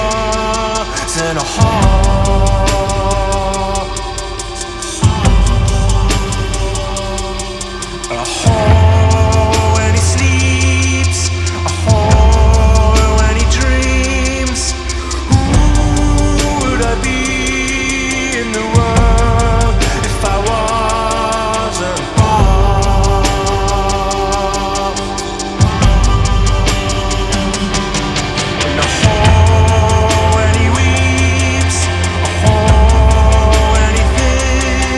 Жанр: Поп музыка / Альтернатива
Indie Pop, Alternative